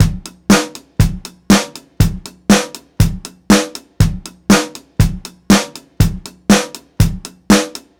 • 120 Bpm Drum Loop Sample D Key.wav
Free breakbeat - kick tuned to the D note. Loudest frequency: 1540Hz
120-bpm-drum-loop-sample-d-key-vsv.wav